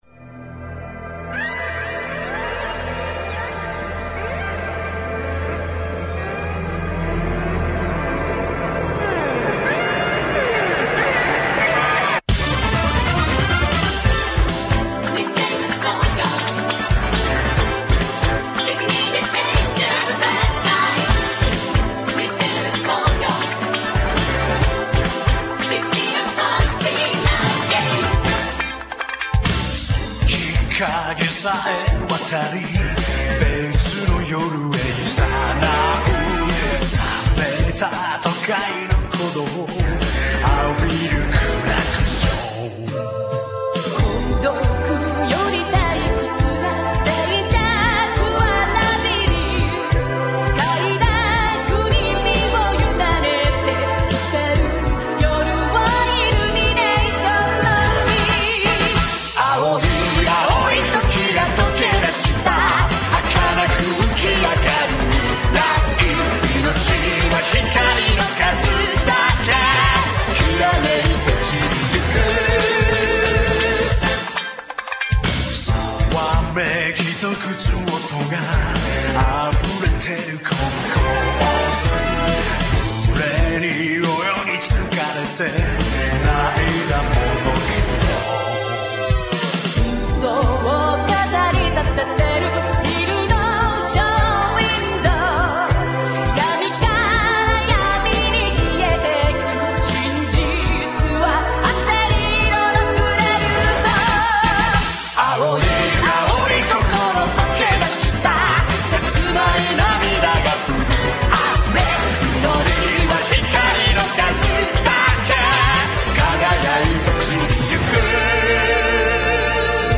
the opening theme.
but this one is Real audio and it's a longer version.